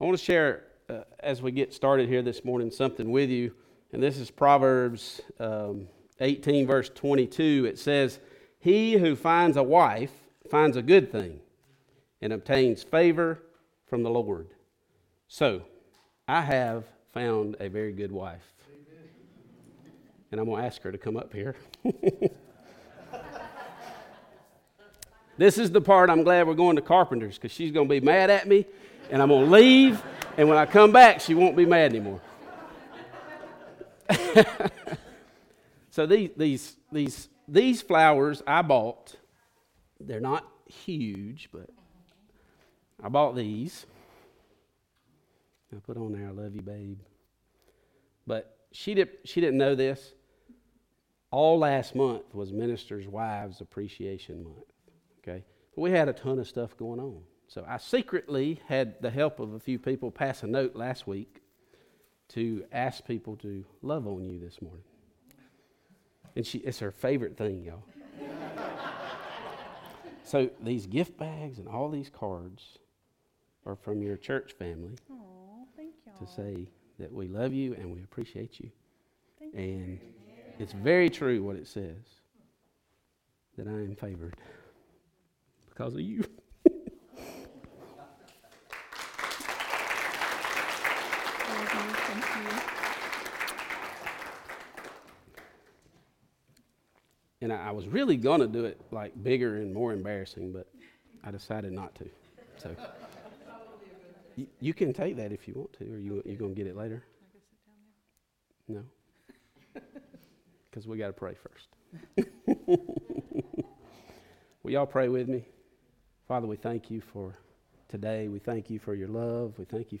Message Type - Sermon
Occasion - Sunday Worship